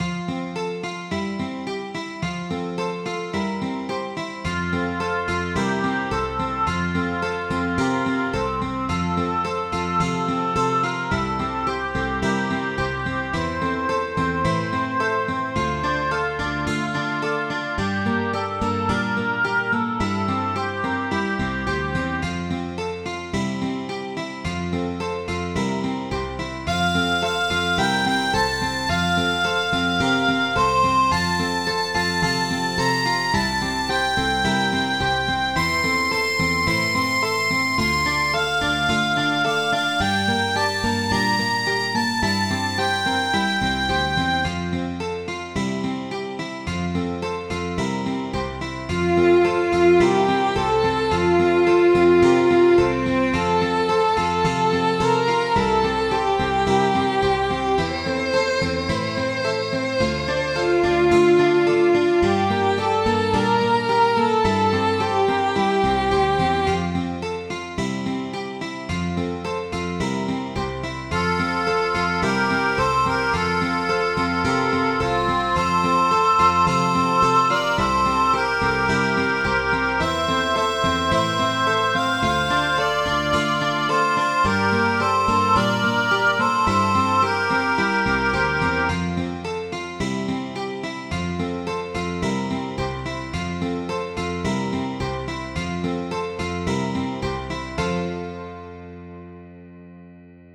Midi File, Lyrics and Information to I Know Where I'm Going